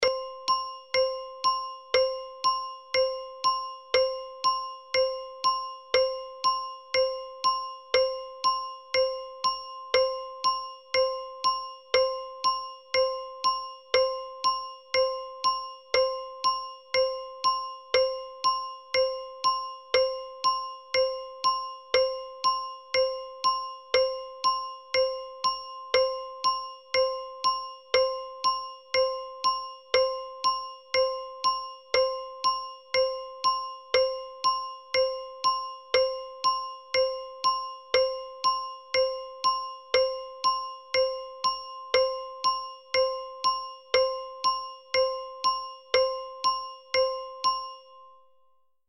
دانلود آهنگ ساعت 1 از افکت صوتی اشیاء
دانلود صدای ساعت 1 از ساعد نیوز با لینک مستقیم و کیفیت بالا
جلوه های صوتی